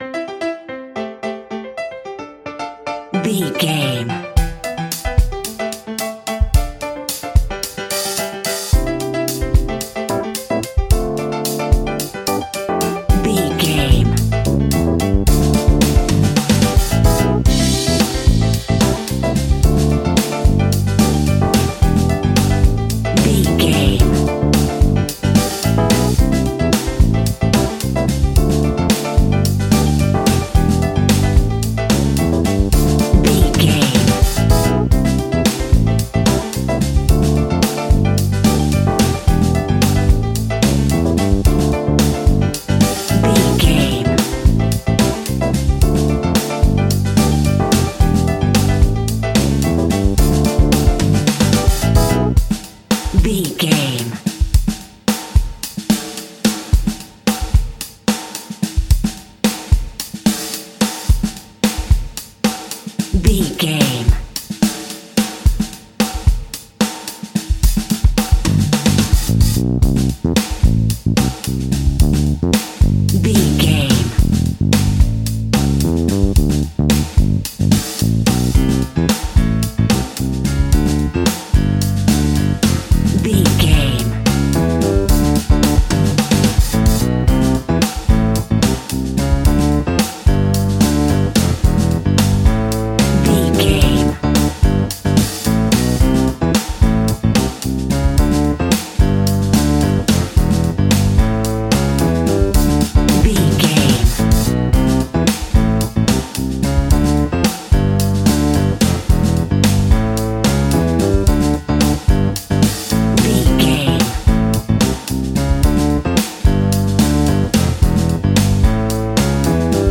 Ionian/Major
flamenco
latin
uptempo
bass guitar
percussion
brass
saxophone
trumpet